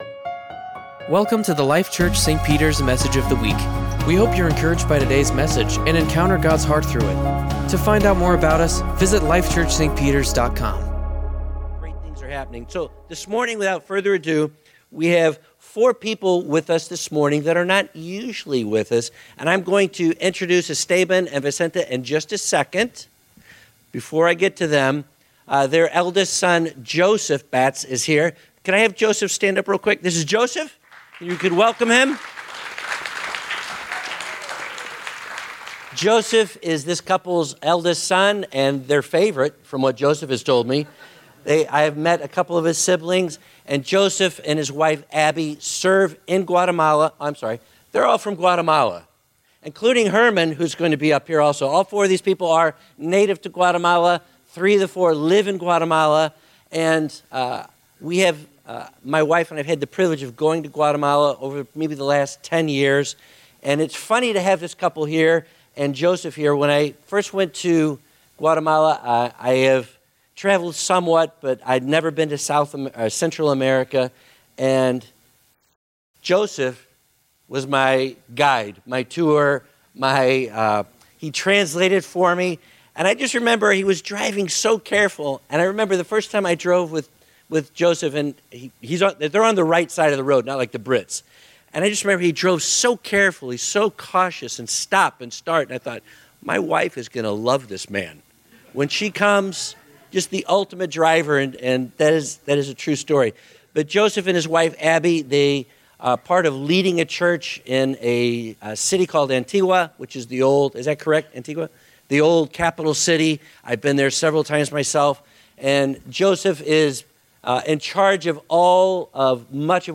Sermons | Life Church - St. Peters